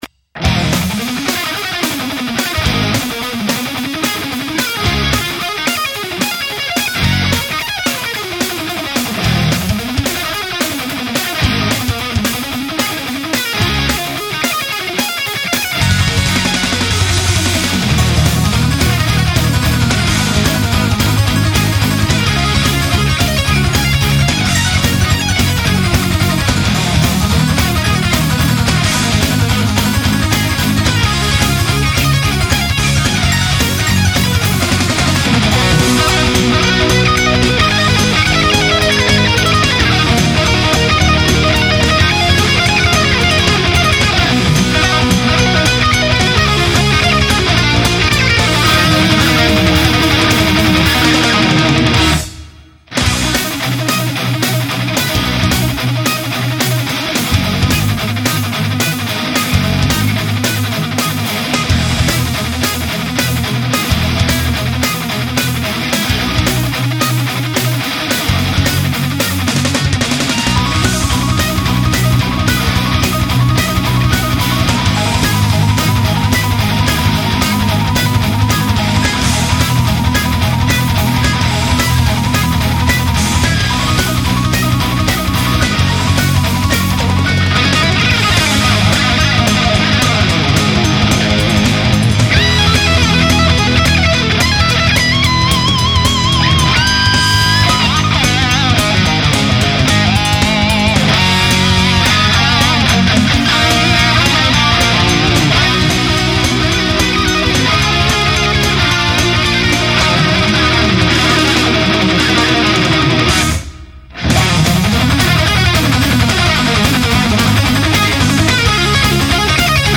볼랜드포럼: 판타스틱한 기타음.....
기타 소리가 예술이 아니라도 들을만 합니다.
팝송 - 기타 소리 죽이는곡.mp3